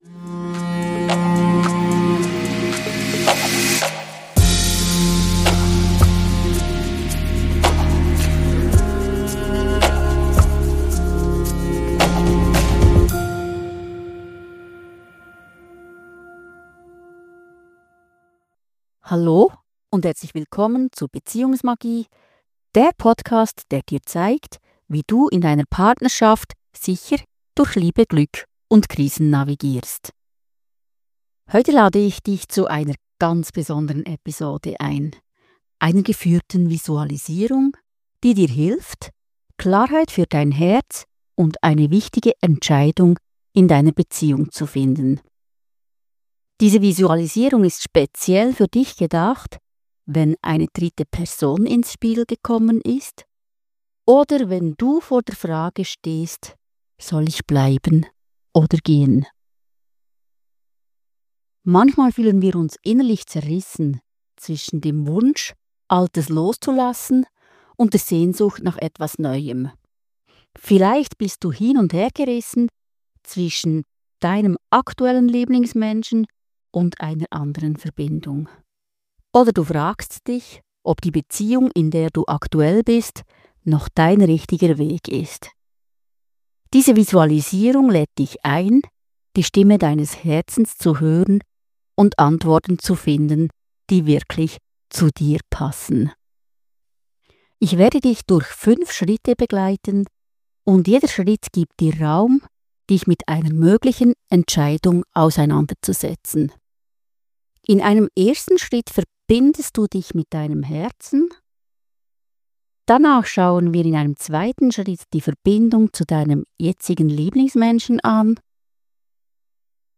Stehst du vor der Entscheidung: Bleiben, gehen oder neu beginnen? Diese geführte Visualisierung bringt dich in Verbindung mit deinem Herzen.